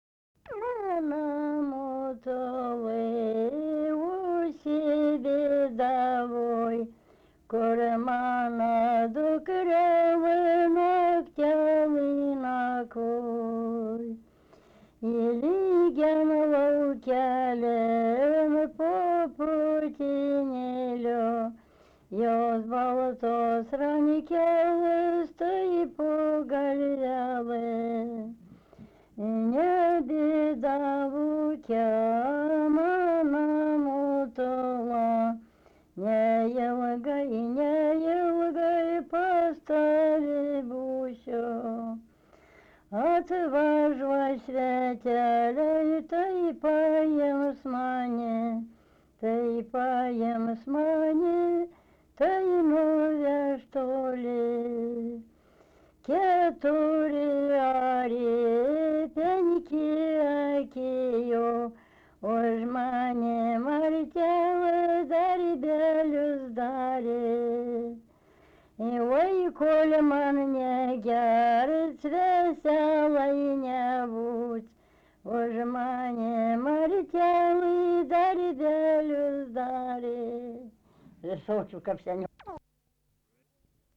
daina, vestuvių
vokalinis